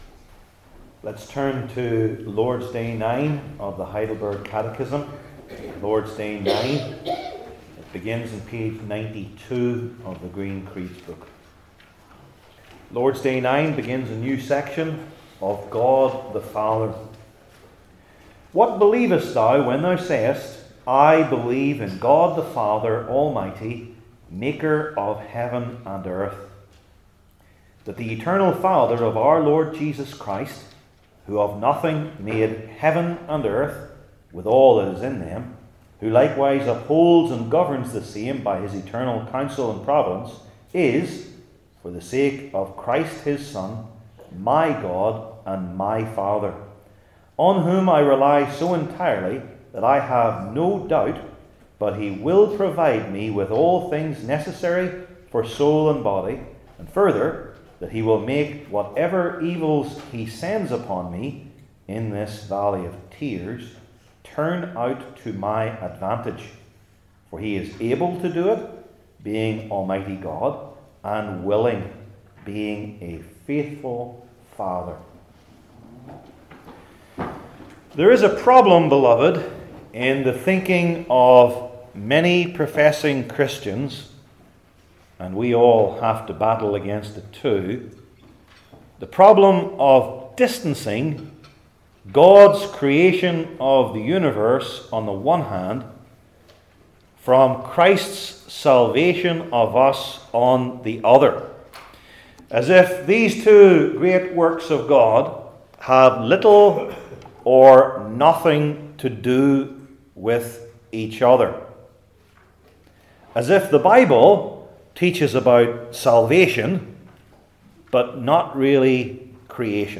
Service Type: Heidelberg Catechism Sermons